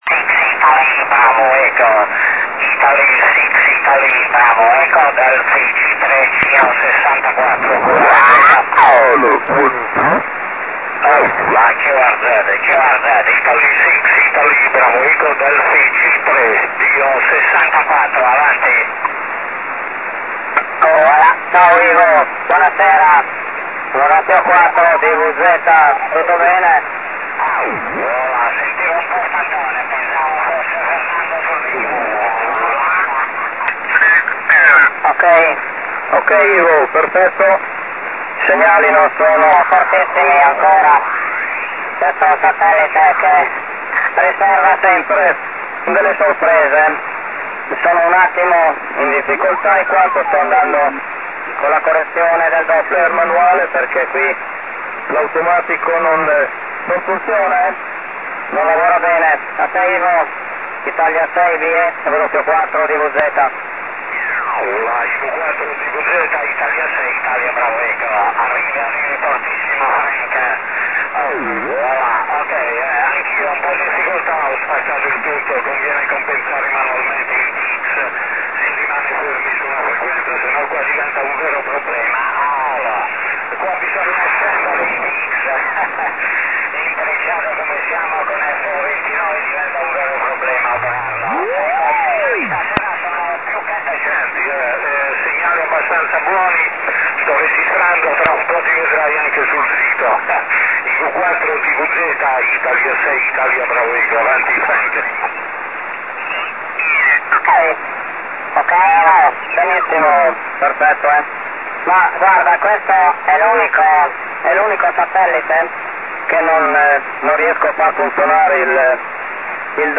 First QSO FONIA